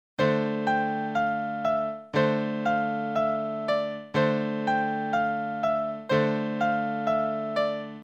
描述：一个简单的钢琴循环，非常灵活，易于在各种流派中使用，用酸和我的Casio ctk591键盘制作。
Tag: 120 bpm Chill Out Loops Piano Loops 1.35 MB wav Key : Unknown